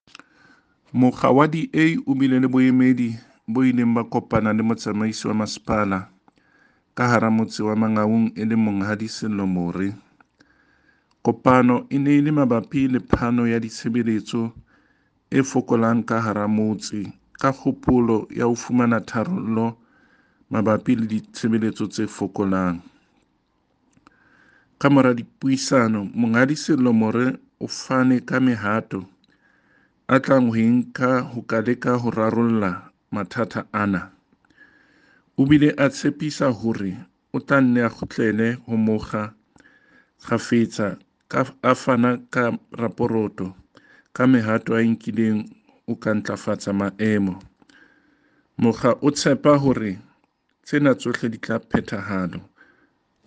Sesotho by Cllr David Masoeu.
Sotho-voice-David.mp3